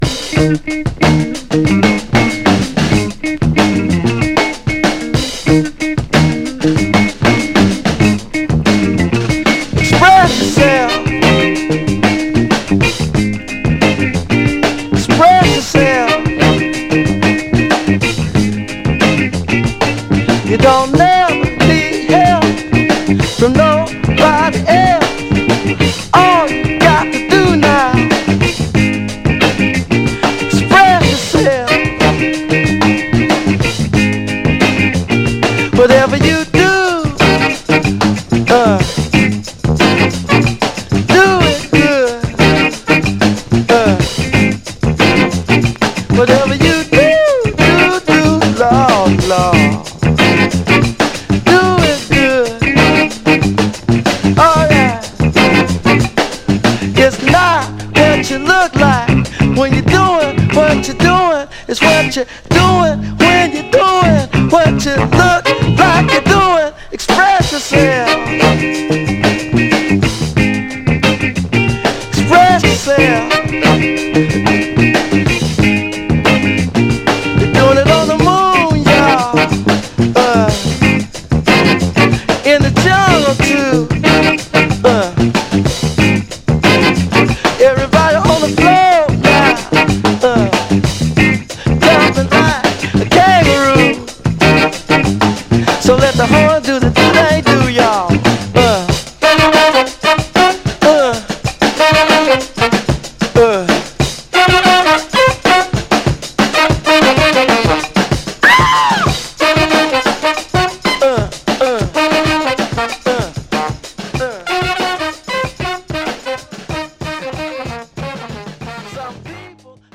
ただし音への影響は少なく、肝心のA面はプレイ良好です。
※試聴音源は実際にお送りする商品から録音したものです※